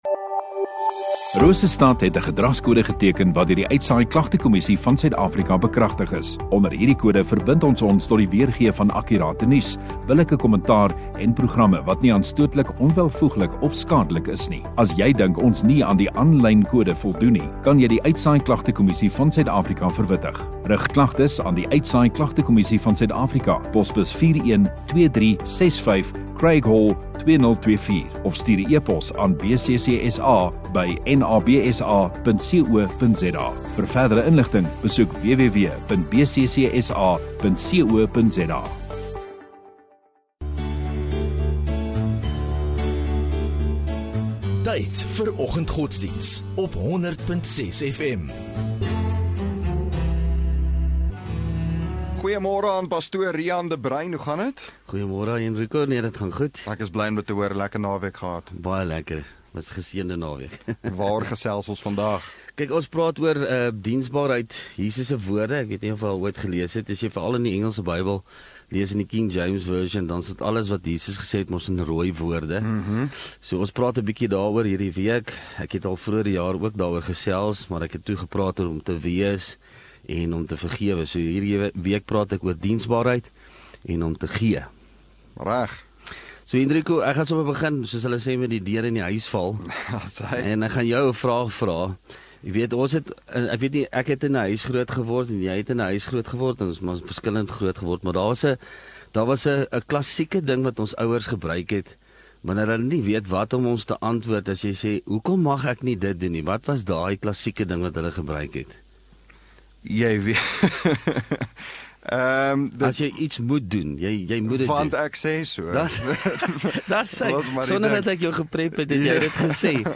28 Jul Maandag Oggenddiens